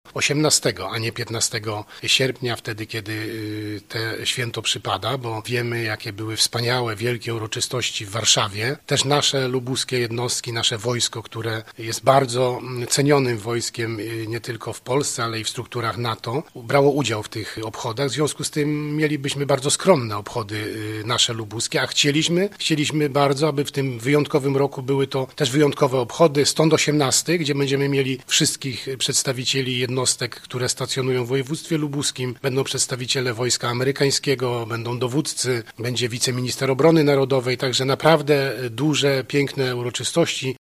Wojewoda Lubuski Władysław Dajczak tłumaczy dlaczego obchody Święta Wojska Polskiego w naszym województwie zostały przełożone z 15 na 18 sierpnia